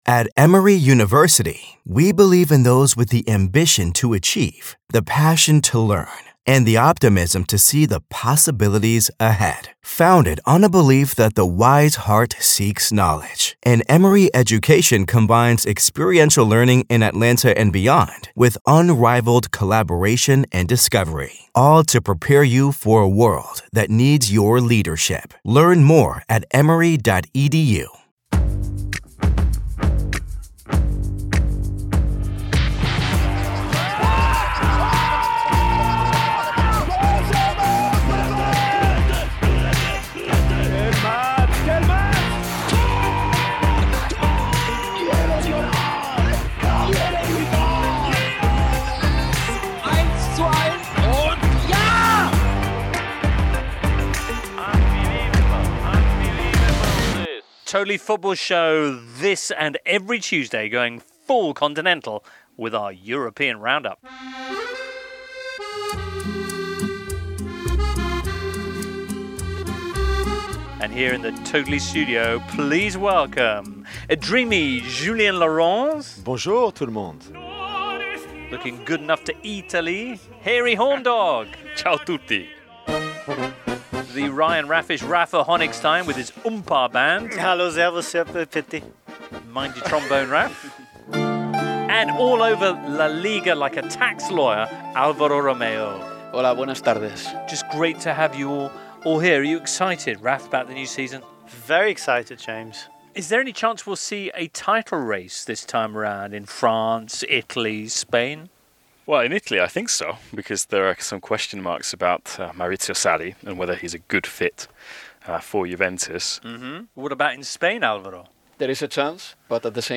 Are we set to see some title races around Europe? The panel preview the coming campaigns in Spain, Italy, Germany and France and offer insight on where Lukaku will end up, whether Bayern will get Sane and what were Juve thinking with their new home shirt.